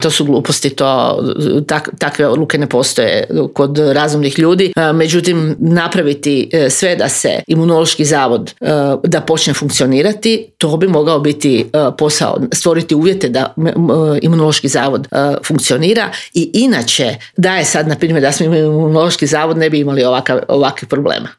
ZAGREB - Nezavisna kandidatkinja za zagrebačku gradonačelnicu Vesna Škare-Ožbolt u razgovoru za Media servis predstavila je svoj plan i program za Grad Zagreb.